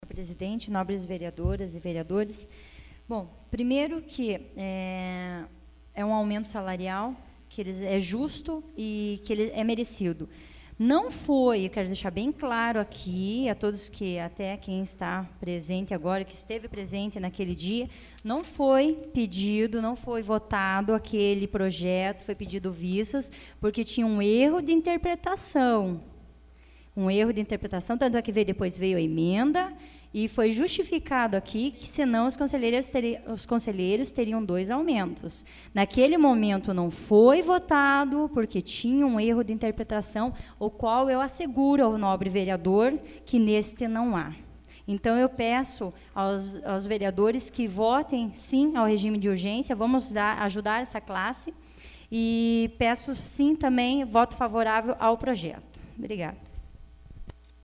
Discussão do Regime de urgênci AVULSO 25/03/2014 Fernanda do Nelsão